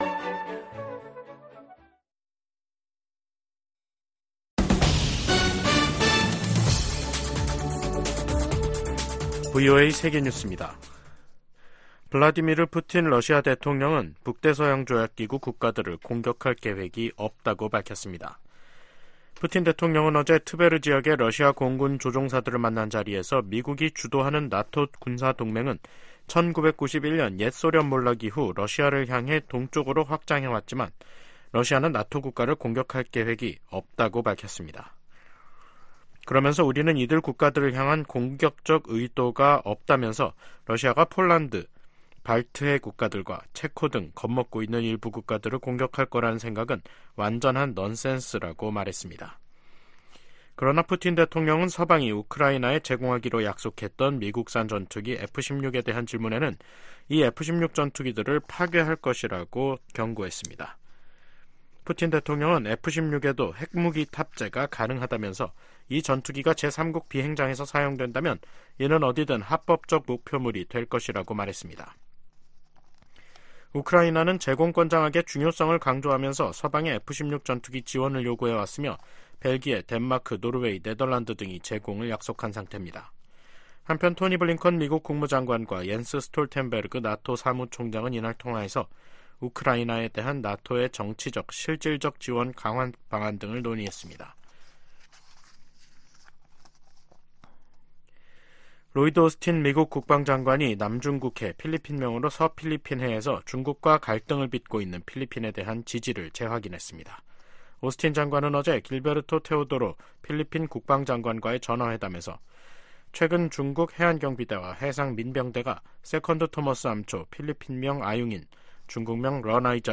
VOA 한국어 간판 뉴스 프로그램 '뉴스 투데이', 2024년 3월 28일 2부 방송입니다. 미국과 한국이 공동으로 북한 국적자 6명과 외국업체 2곳에 대한 제재를 단행했습니다. 북한이 최근 원심분리기 시설을 확장하고 있는 것으로 보인다는 보도가 나온 가운데 미국 정부는 위험 감소 등 북한과 논의할 것이 많다는 입장을 밝혔습니다. 중국이 미일 동맹 격상 움직임에 관해, 국가 간 군사협력이 제3자를 표적으로 삼아선 안 된다고 밝혔습니다.